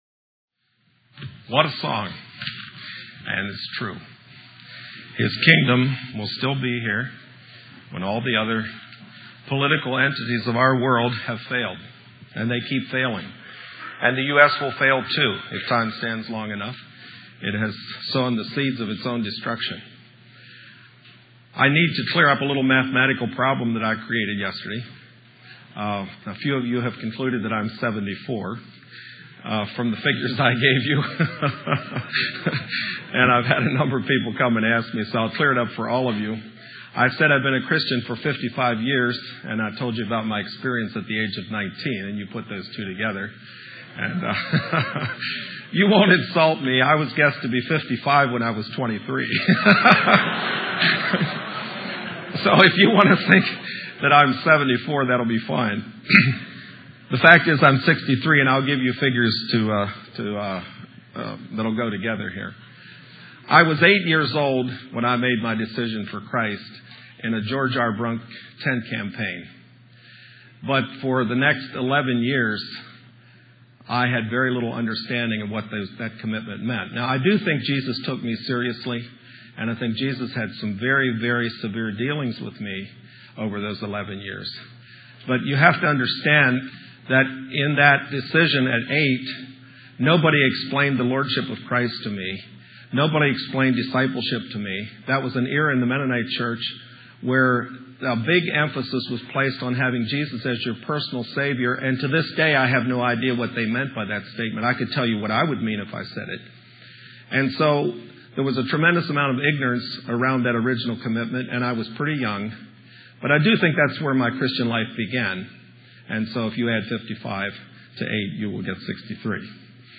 Sermon set